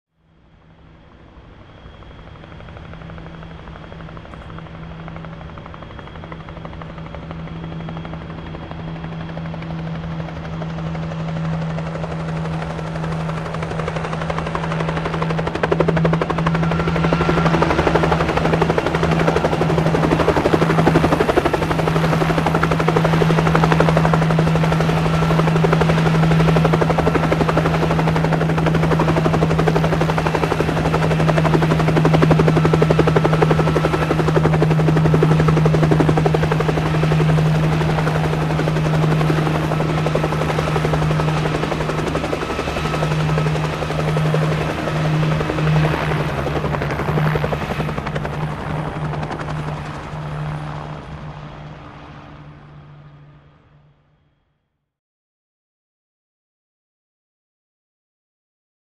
Helicopter In And Hover Close Up With Heavy Blade Definition